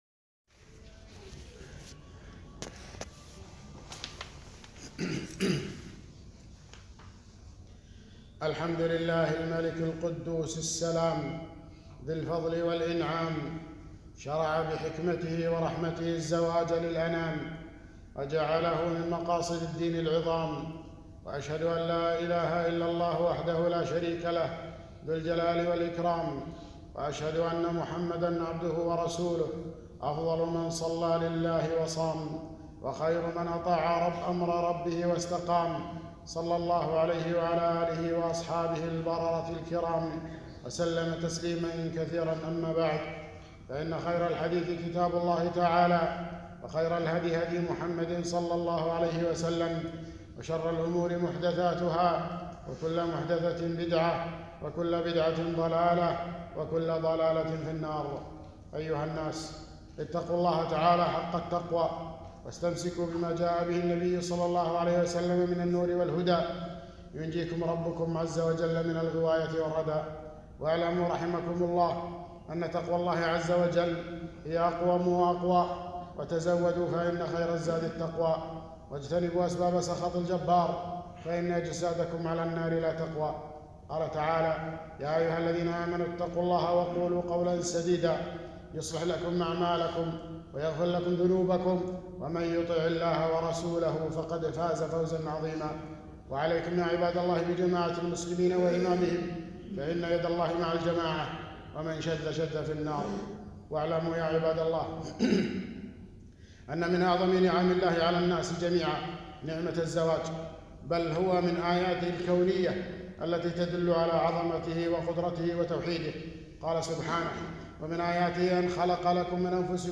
خطبة - منكرات الأفراح والأعراس